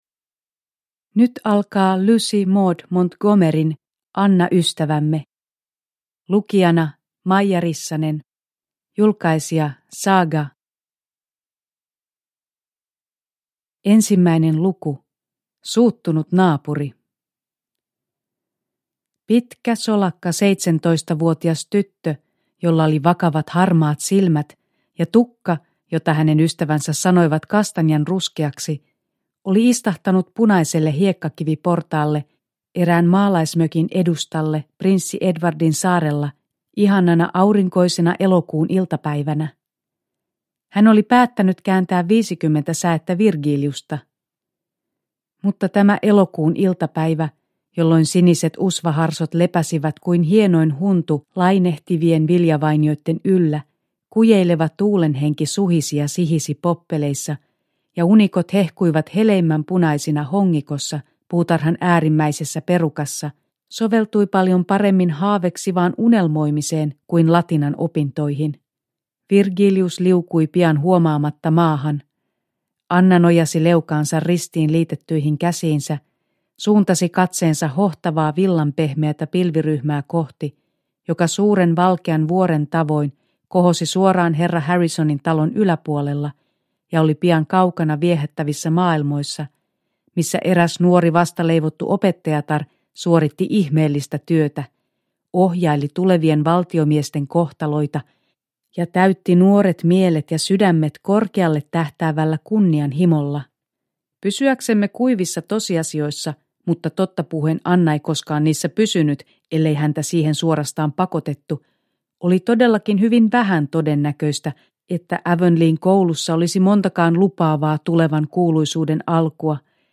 Anna ystävämme – Ljudbok – Laddas ner